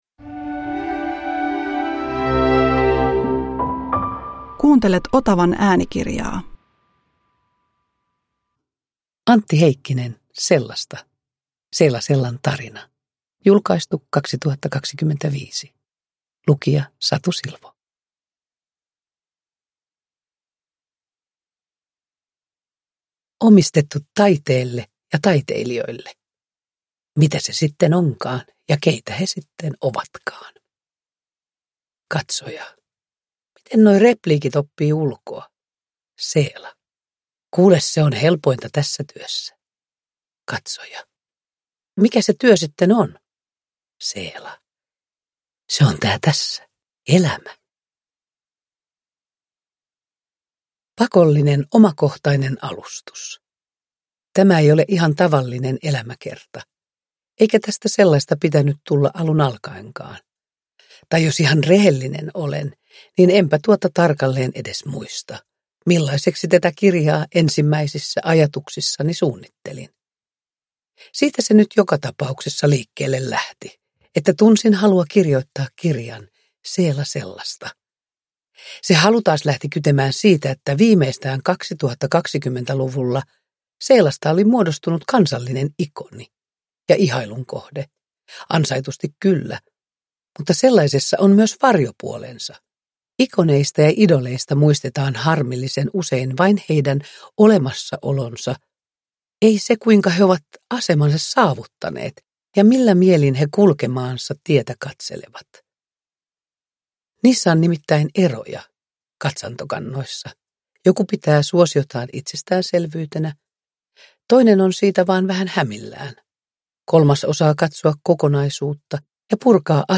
Sellasta – Ljudbok
Uppläsare: Satu Silvo